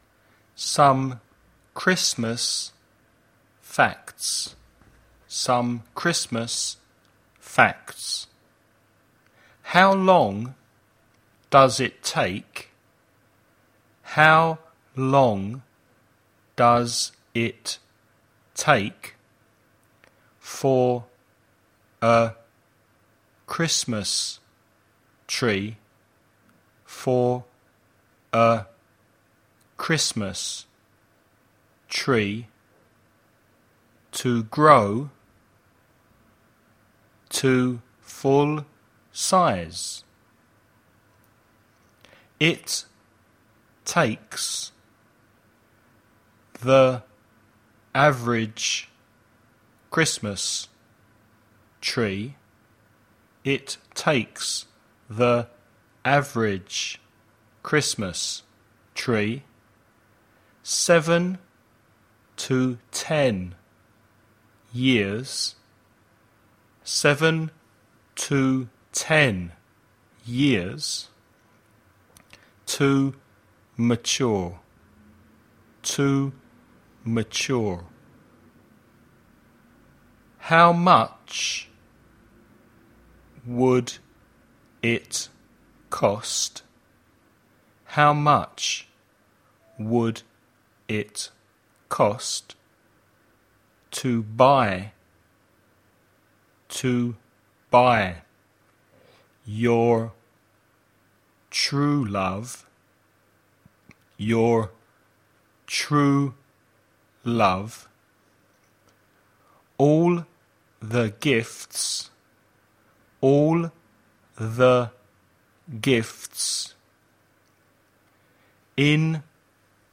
DICTATION / DICTADO
1. Listen to the text read at normal speed.
dictation2.mp3